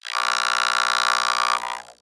robotscream_7.ogg